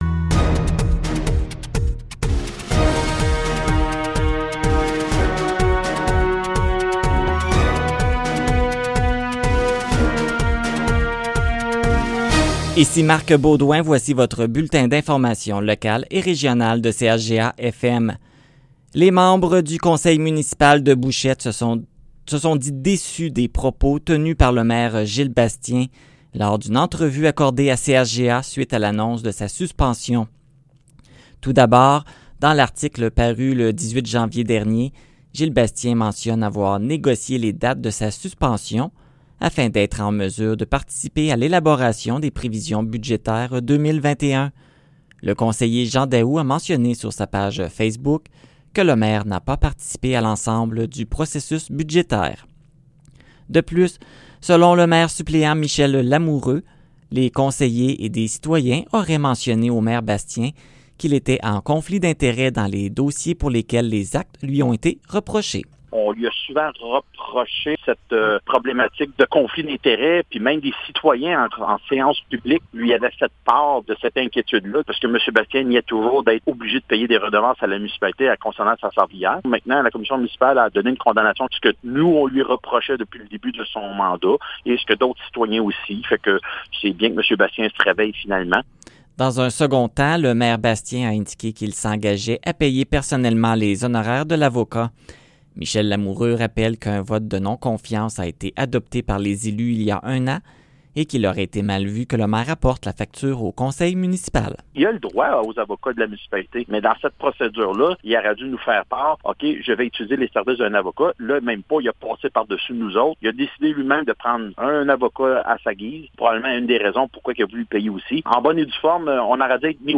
Nouvelles locales - 26 janvier 2021 - 15 h